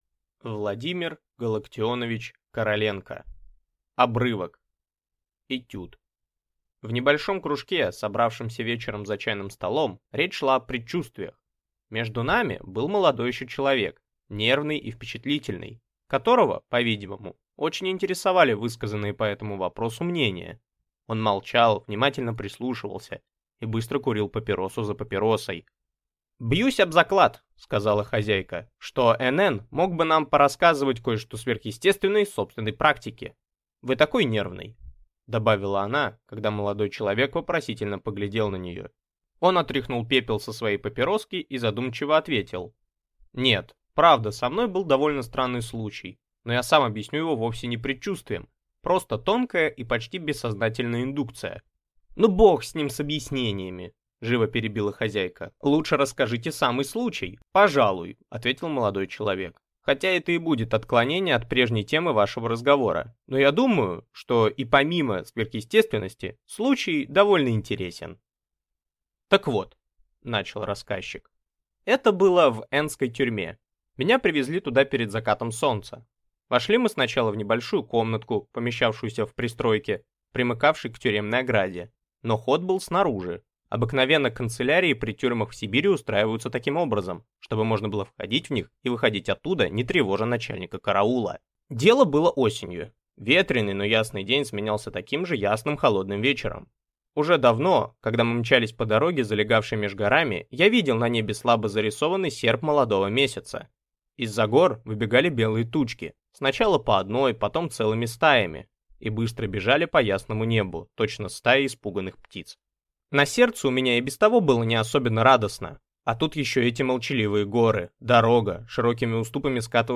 Аудиокнига Обрывок | Библиотека аудиокниг